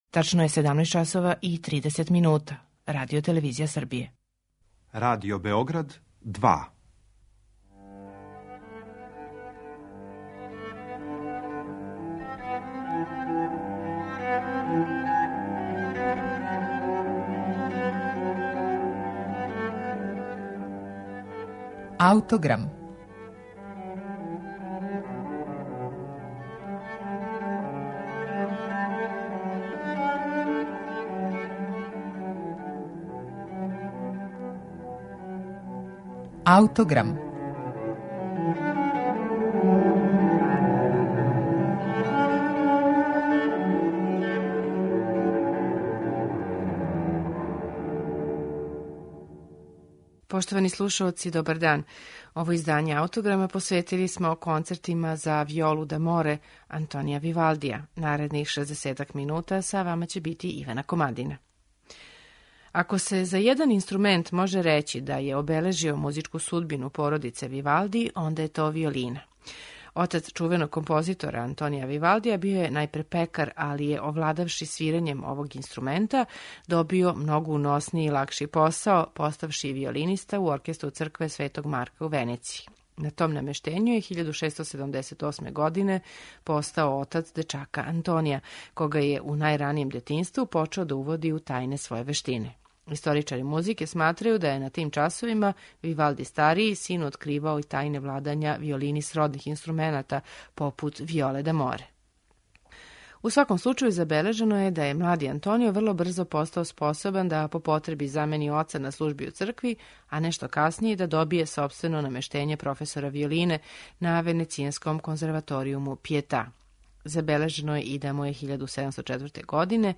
Концерти за виолу д'аморе Антонија Вивалдија
У 18. веку виола д'аморе је по величини била приближна данашњој виоли: најчешће је израђивана са 14 жица, од којих је 7 било уобичајено затегнуто преко врата инструмента, а 7 испод њих, са задатком да појачају резонирање оних на којима се свира.